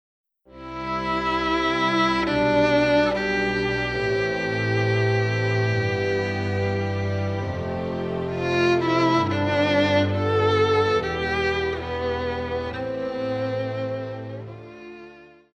Pop
Violin
Band
Instrumental
World Music
Only backing